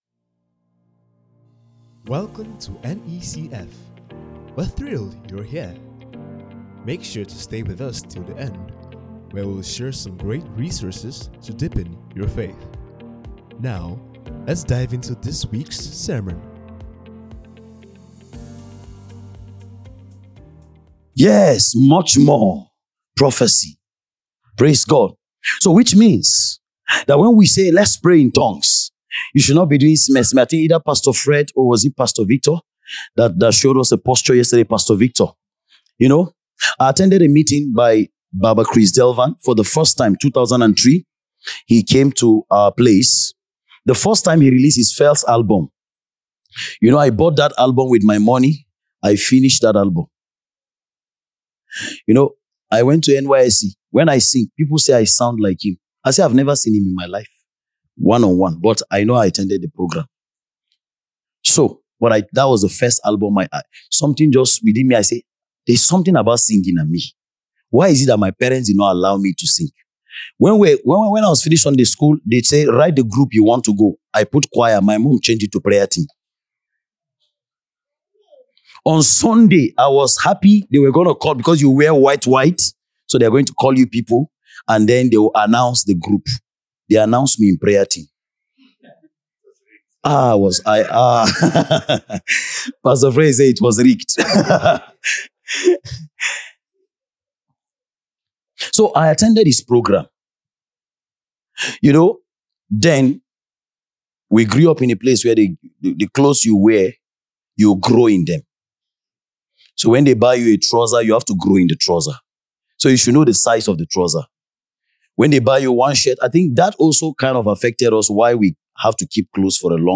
Near East Christian Fellowship Sermons Podcast - The Gifts of the Spirit 6 | The Believer's conference 2025 day 5 | Free Listening on Podbean App
The 6th message on The Gifts of the Spirit from the 2025 Believer's conference, day 5